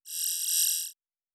Sci-Fi Sounds / Interface